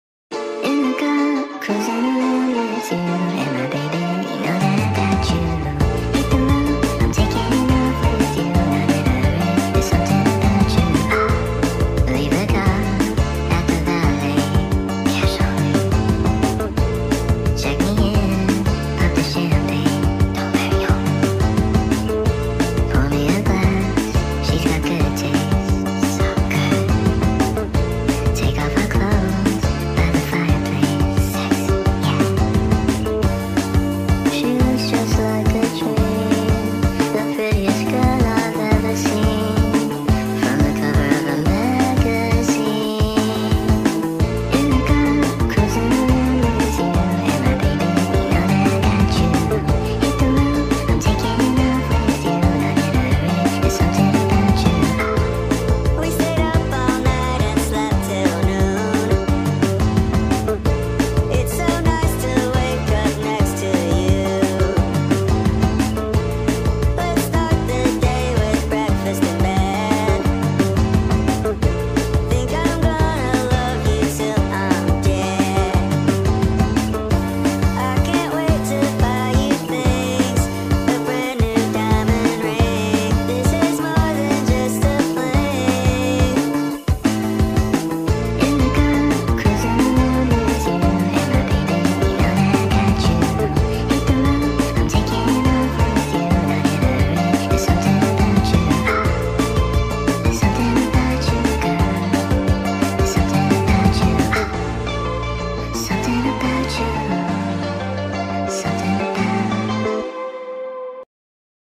ریمیکس صدای بچه افزایش سرعت